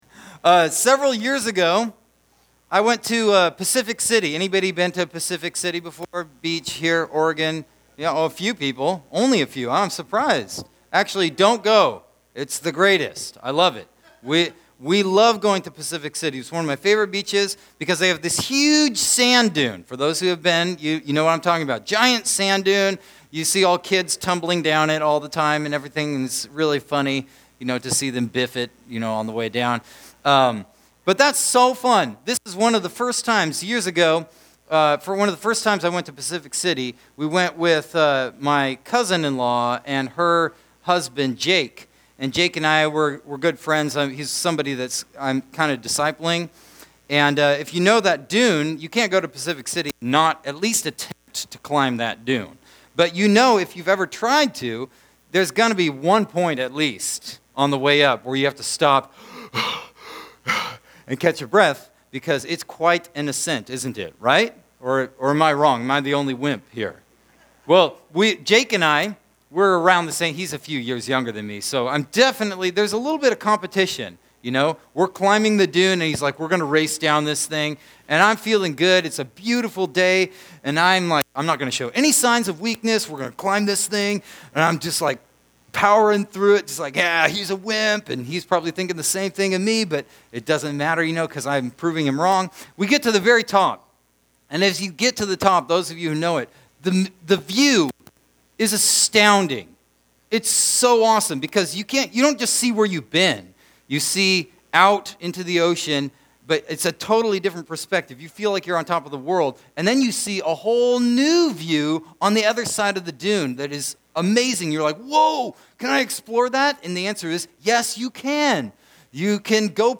Sermons - Wapato Valley Church